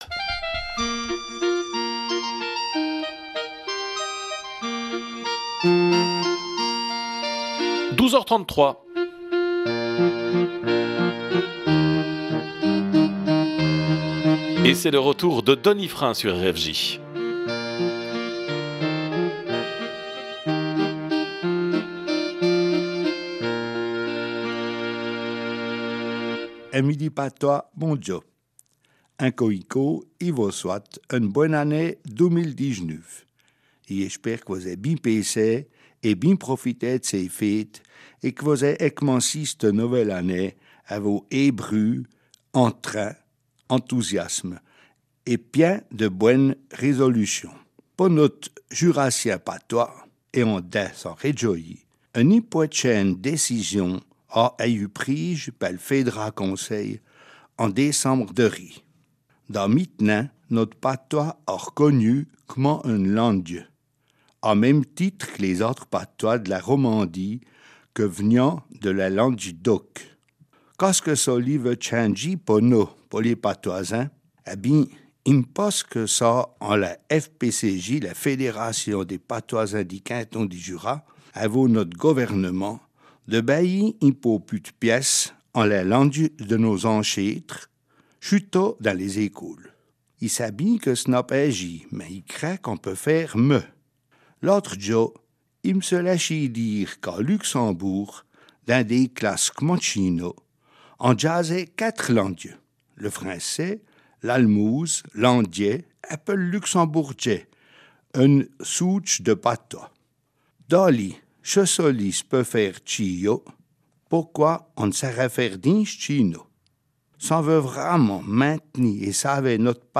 Rubrique en patois du 27 janvier 2019